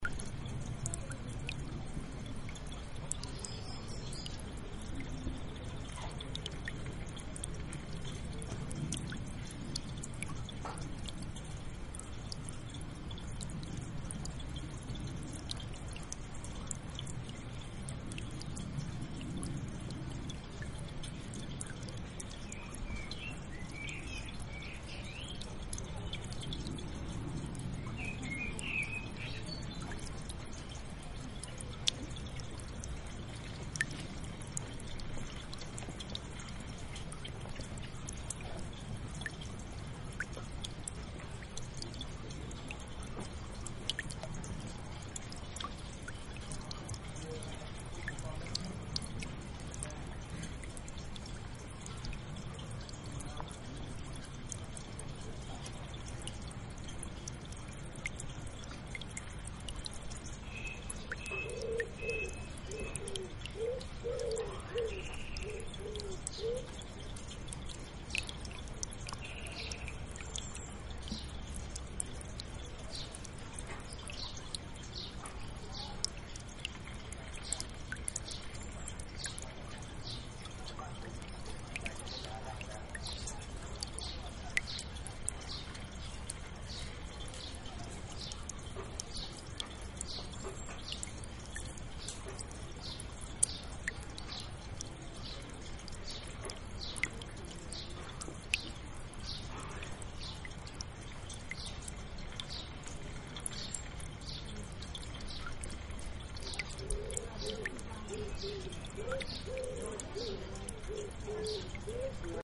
Sounds in the fountain in the Adarve garden.